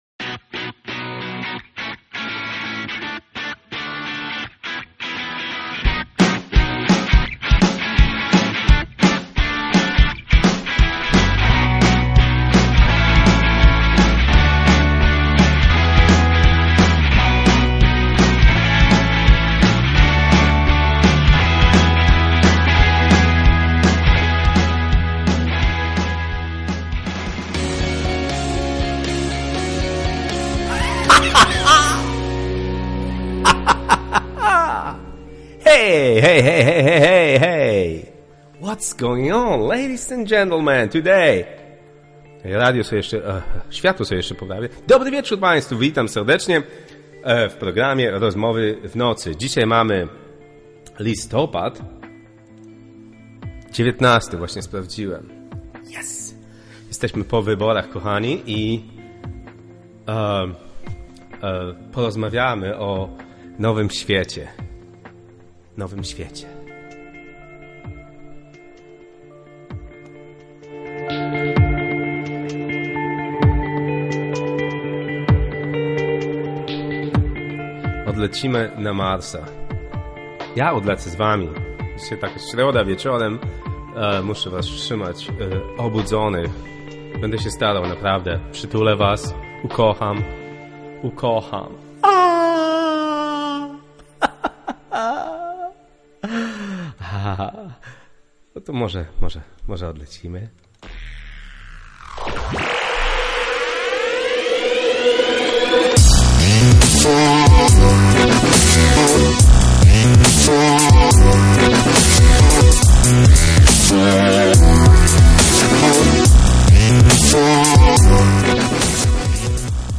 Rozmowy w Nocy to internetowy talk-show na żywo z udziałem słuchaczy w środy 23.00. Jest to audycja o życiu ludziach i ich problemach. Nasze tematy to człowieczeństwo, polityka, równouprawnienie, choroby dzisiejszego społeczeństwa, krytyka instytucji kościelnych nie jest nam obca, nauka, Bóg, wiara, religia, nasze lęki.